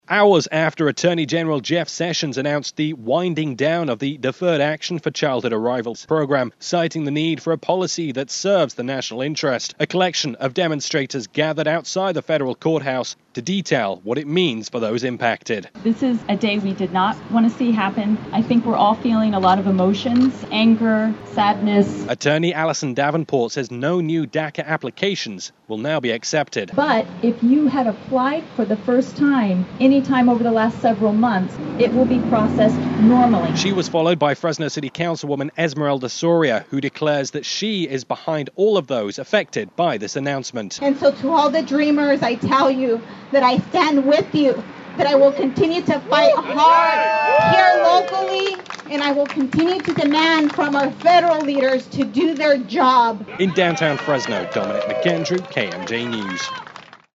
He was the first of five to speak outside Downtown Fresno’s Federal Courthouse Tuesday morning, to reject the move made by Attorney General Jeff Sessions only hours earlier.